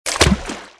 chapuzon
Sonido FX 13 de 42
chapuzon.mp3